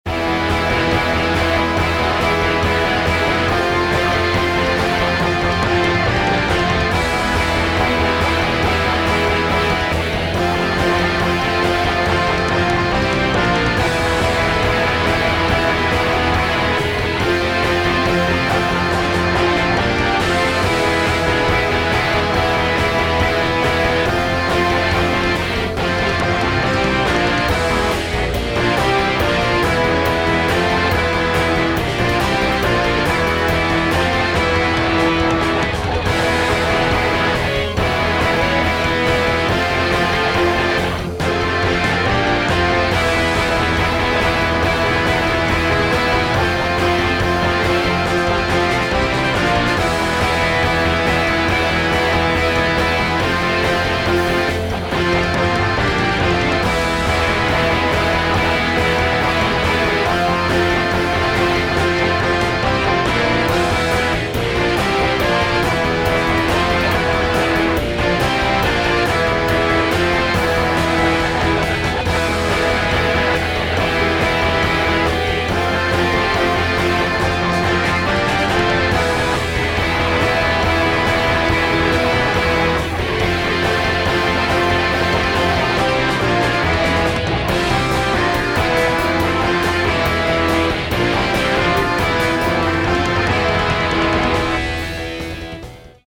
midi-demo 2